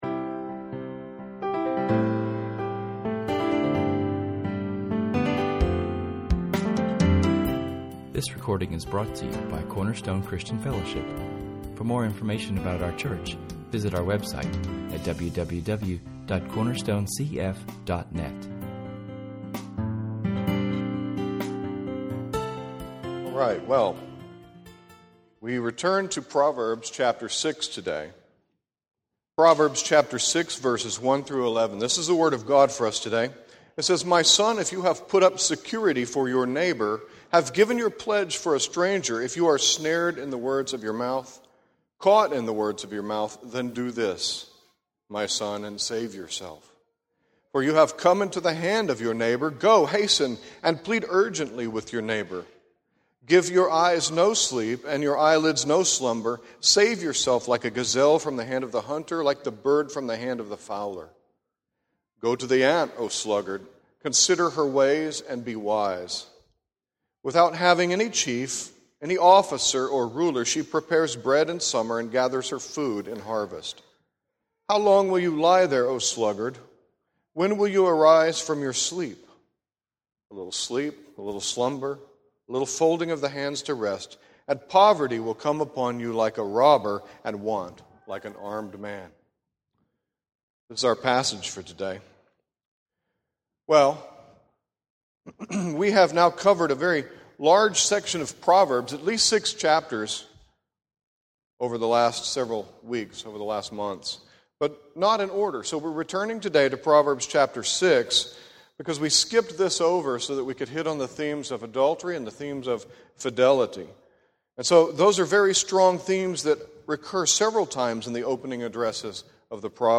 In this sermon, we open up [esvignore]Proverbs 6:1-11[/esvignore]. Here we learn some important lessons for those who would be contributing members of God’s society on earth, the church. We are to be men and women of character, and this includes how we deal with our friends financially, how we deal with money in general, and our work ethic.